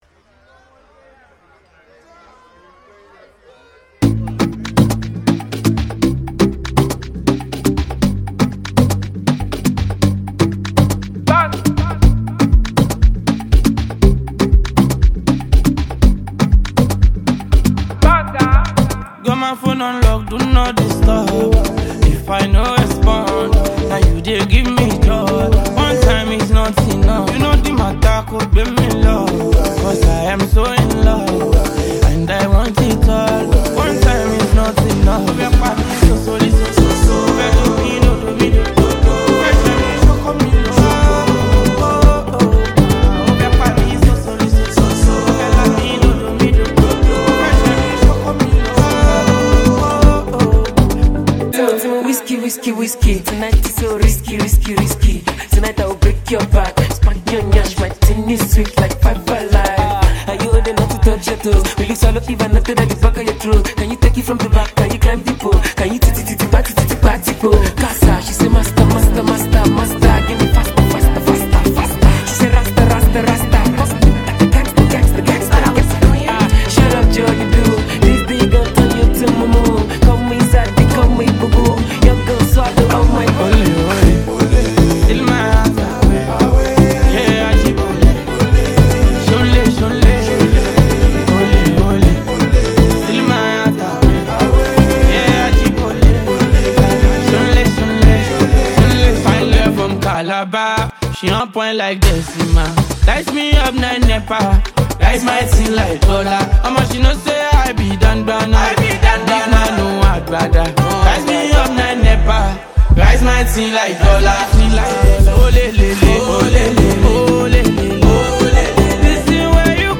high-energy and captivating track
Afrobeat music